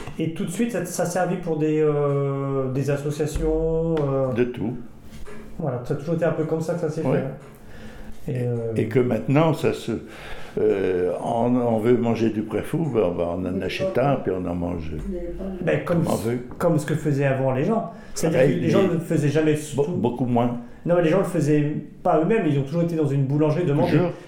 Enquête autour du préfou
Témoignage